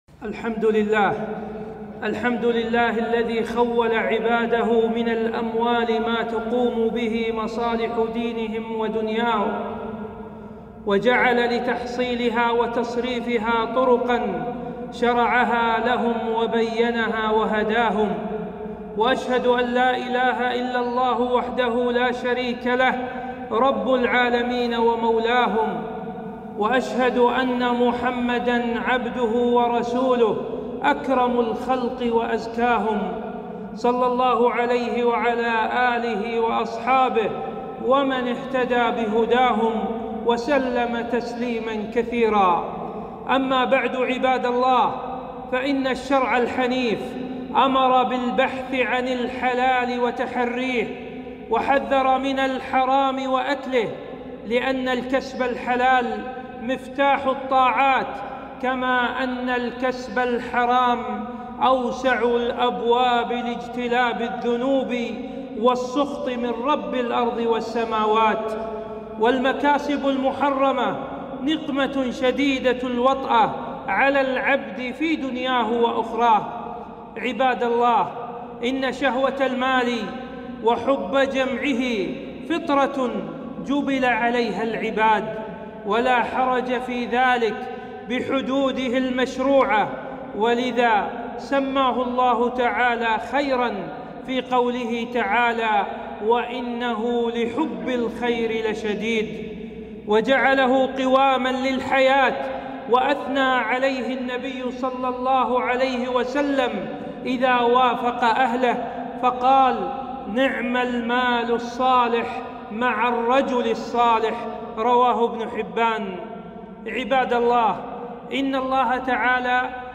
خطبة - التحذير من الكسب الحرام وغسل الأموال